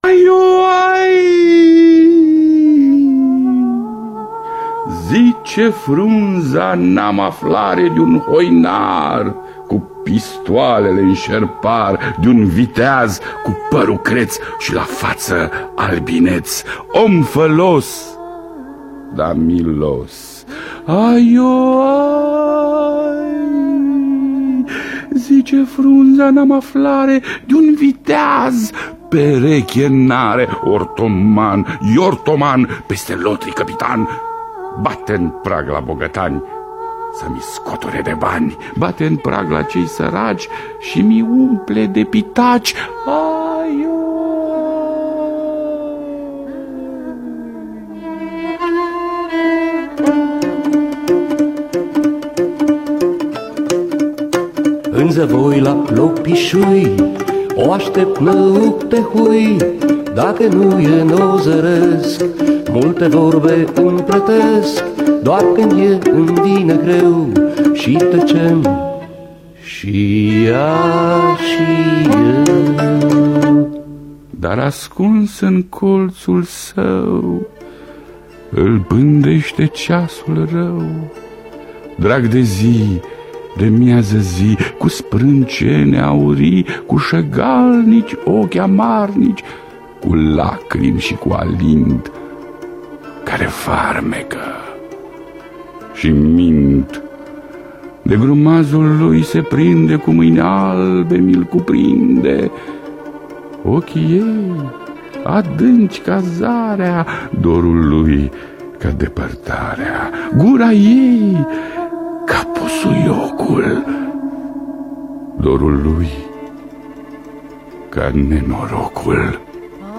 Salba Mălinei de Dominic Stanca – Teatru Radiofonic Online
cobze
vioară